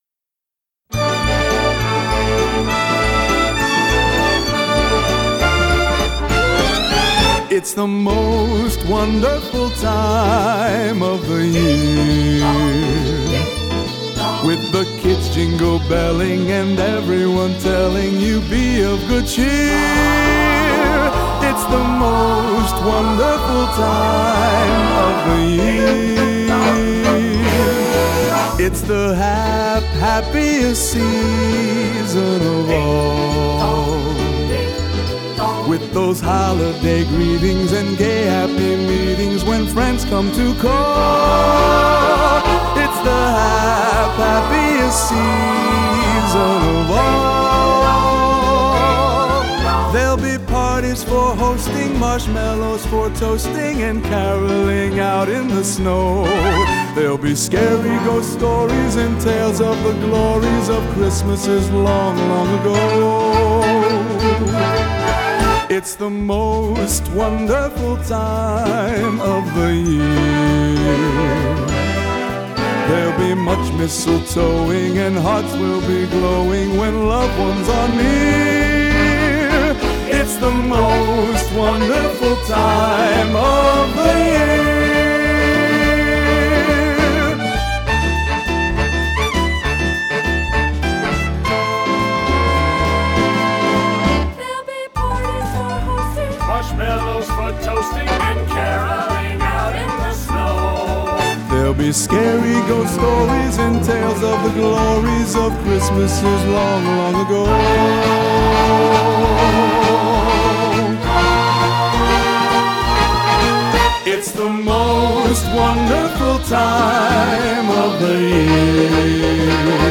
Cover w/ solo